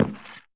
creaking_heart_step1.ogg